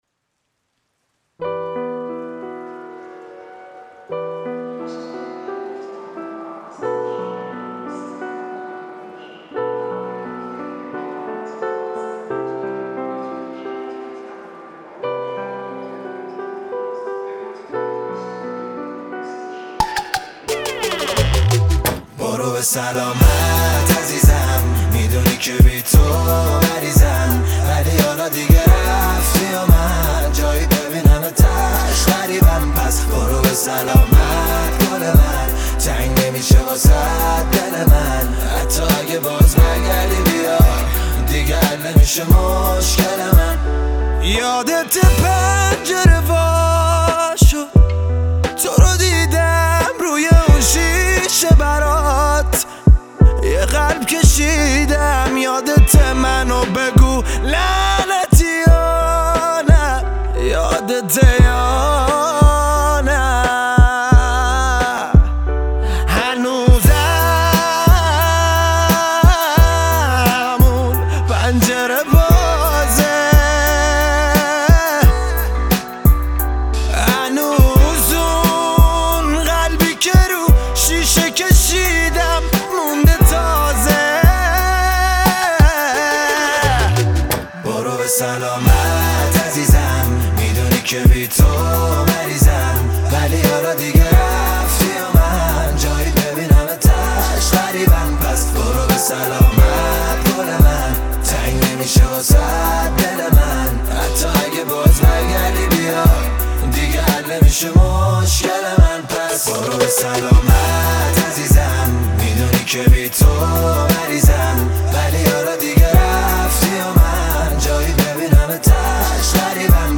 خواننده جوان و پرمخاطب موسیقی پاپ کشور